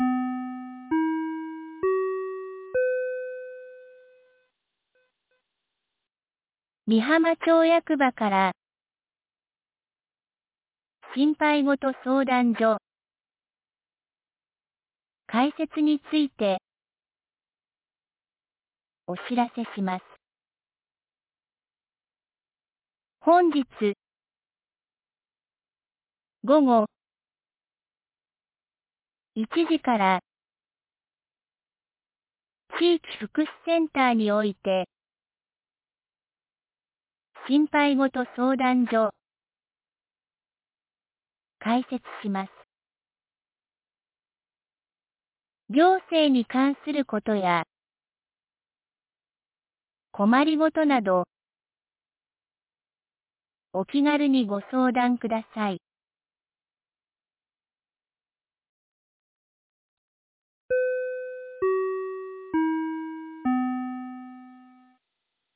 美浜町放送内容 2026年02月04日07時49分 （町内放送）心配ごと相談所
2026年02月04日 07時49分に、美浜町より全地区へ放送がありました。